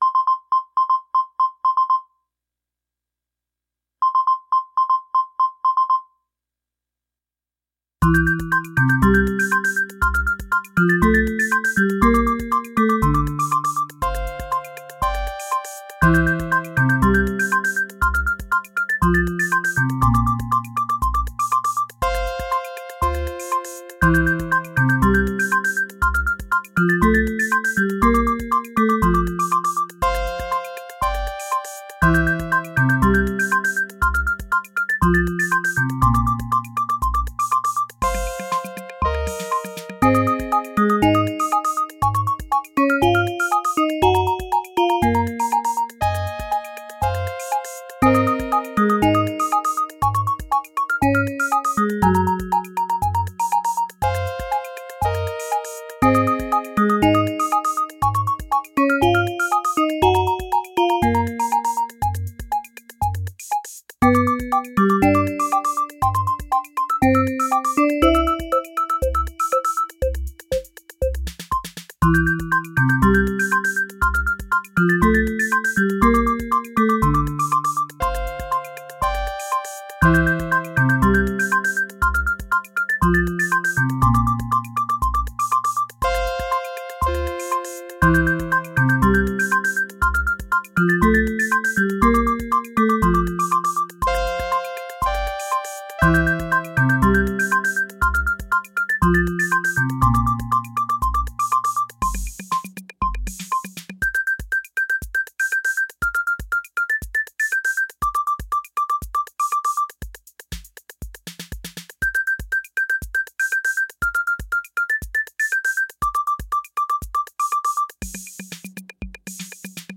• Roland D-110: Custom Patch
• Synthstrom Deluge: Drum sequence using 808 presets
The drum changes were made live.
The song is in mono.
And of course the drum sequence is cheesy.